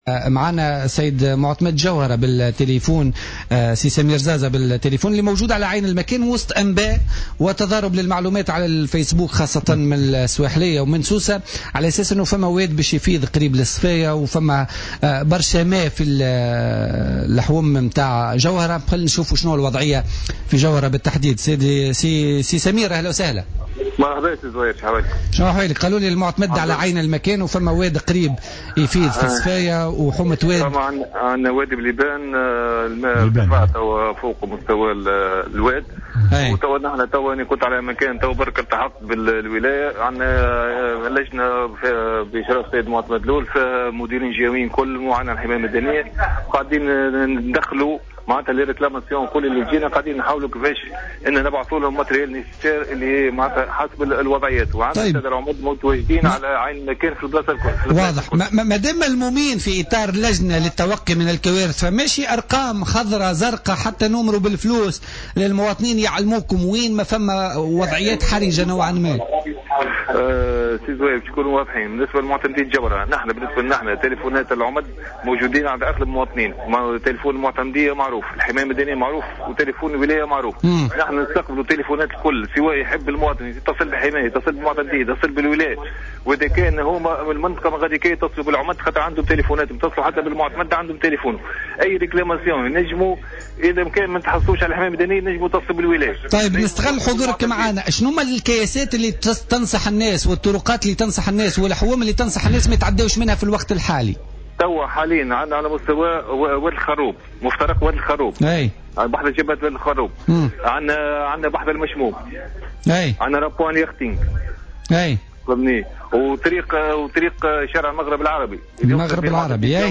أكد معتمد جوهرة سمير زازا في مداخلة له اليوم في برنامج "بوليتيكا" ارتفاع منسوب المياه بوادي بليبان، داعيا المواطنين إلى توخي الحذر و عدم المجازفة.